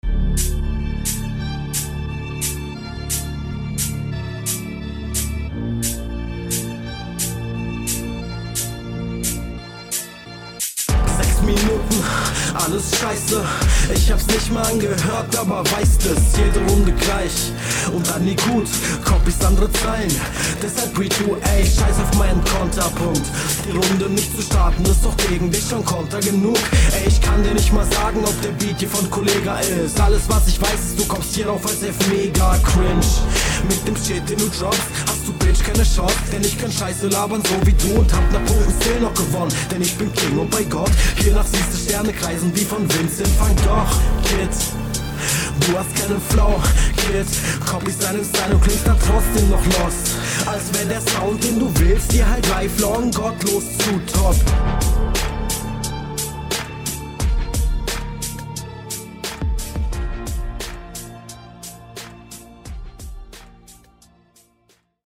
Bitte cutte die Atmer raus, aber ansonsten stabil und zum glück nicht 6 min.